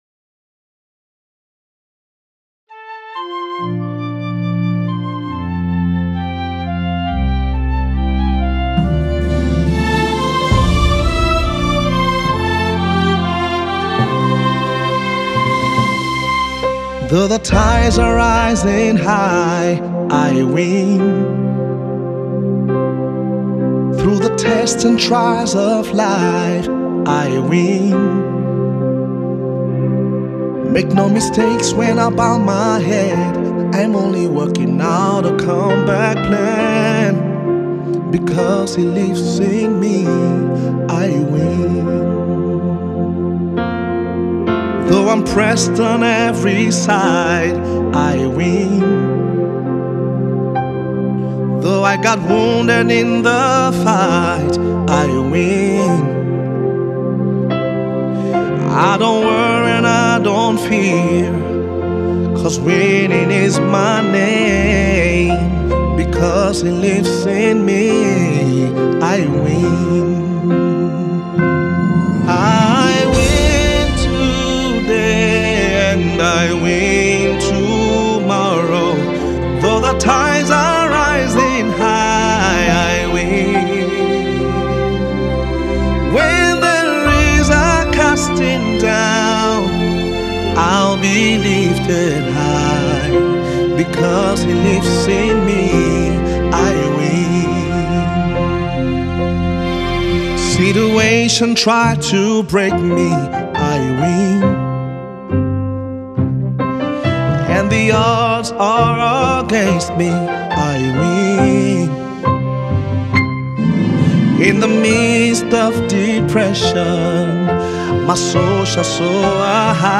inspiring and soul lifting single
a simple sing along song